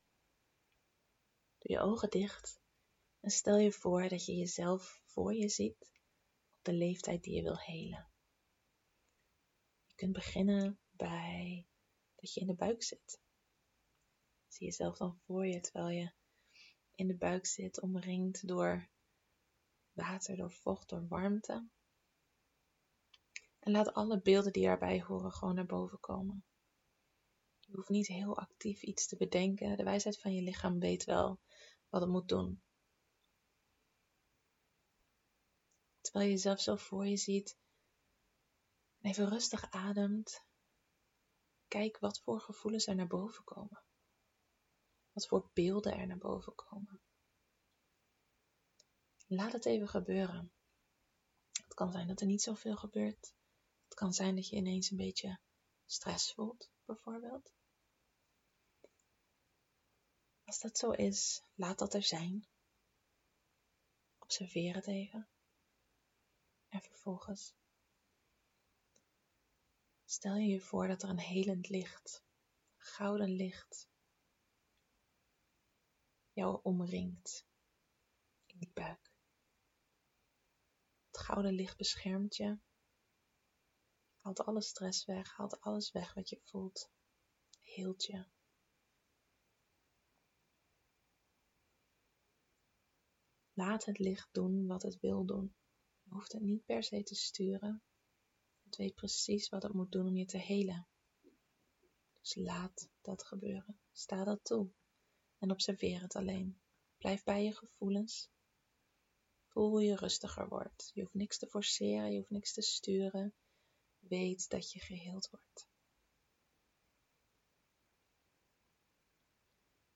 Dit is een visualisatie om je innerlijke kindje te helen, vanaf de buik tot en met volwassenheid. Er zijn vaak zoveel dingen die we opslaan, die het kleine kindje in ons tegen ons wil zeggen.